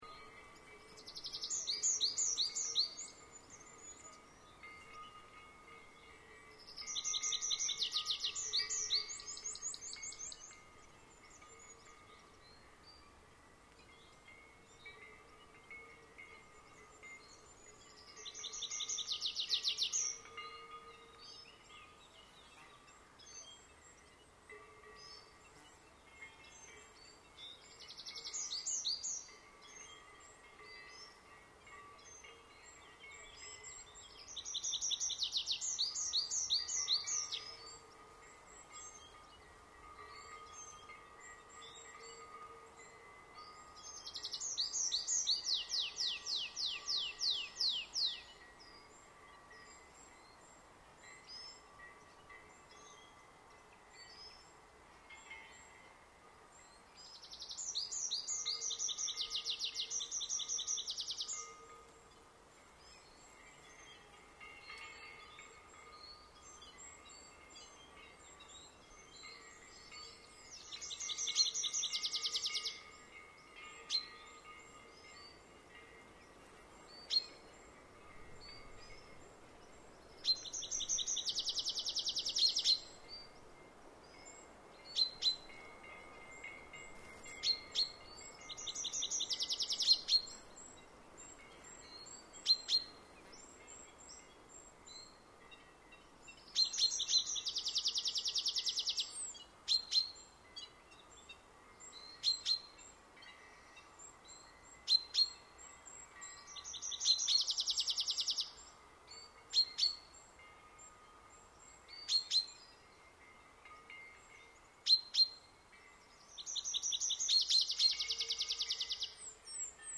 6. Northern Flicker (Colaptes auratus)
Sound: A loud, repeating “wick-a-wick-a-wick!”